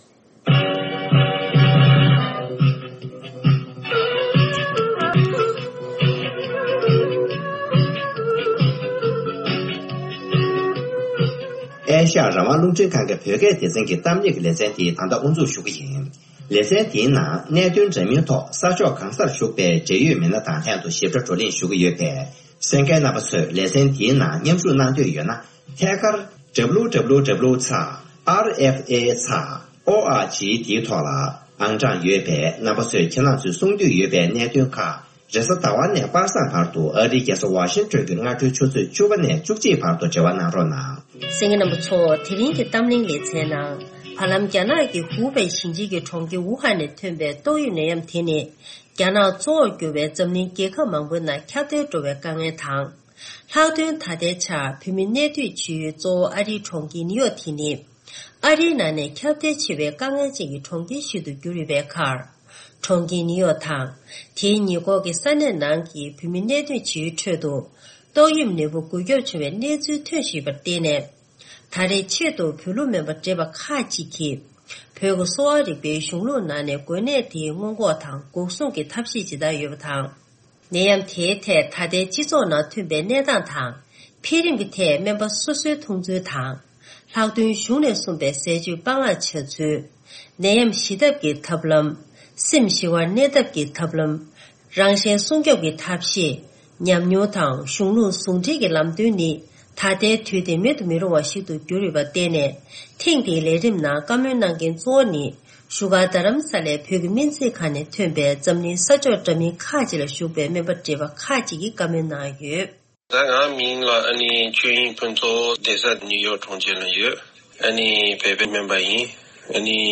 འཛམ་གླིང་ས་ཕྱོགས་འདྲ་མིན་ཁག་ཅིག་ལ་བཞུགས་པའི་བོད་ལུགས་སྨན་པ་བགྲེས་པ་ཁག་ཅིག་གིས་ཏོག་དབྱིབས་འགོས་ནད་དེར་བོད་ཀྱི་གསོ་བ་རིག་པའི་གཞུང་ནས་སྔོན་འགོག་དང་འགོག་སྲུང་གི་ཐབས་ཤེས་ཇི་ལྟར་ཡོད་པ་དང་། སྤྱི་ཚོགས་ནང་ནད་ཡམས་དེའི་གནས་སྟངས་འགྱུར་བ་དང་འཕེལ་རིམ་ཐད་སྨན་པ་སོ་སོའི་མཐོང་ཚུལ་དང་། ལྷག་དོན་གཞུང་ནས་གསུང་པའི་ཟས་སྤྱོད་ཀྱི་སྤང་བླངས་བྱ་ཚུལ་། ནད་ཡམས་ཞི་ཐབས་དང་སེམས་ཞི་བར་གནས་ཐབས་ཀྱི་ཐབས་ལམ་། རང་གཞན་སྲུང་སྐྱོབ་ཀྱི་ཐབས་ཤེས་སོགས་ཉམས་མྱོང་དང་གཞུང་བཟུང་འབྲེལ་གྱིས་ལམ་སྟོན་གནང་བ་ཞིག་གསན་རོགས་གནང་།